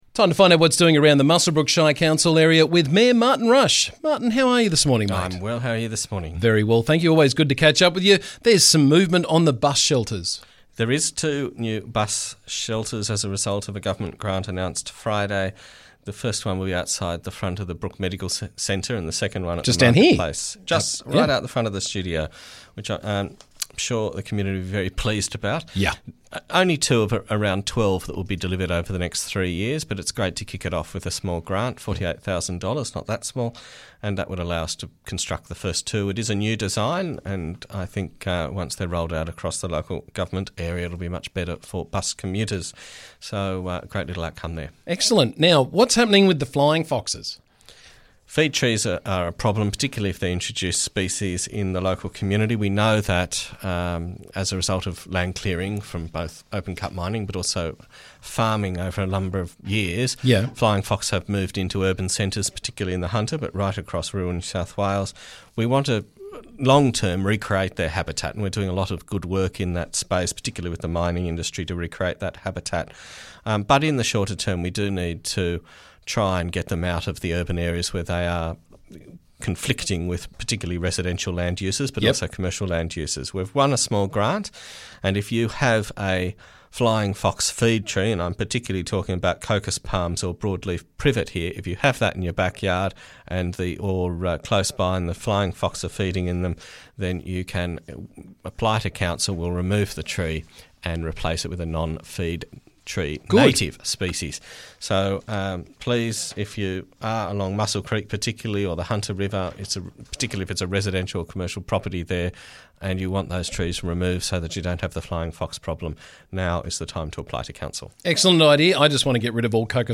Muswellbrook Shire Council Mayor Martin Rush joined me to talk about the latest from around the district.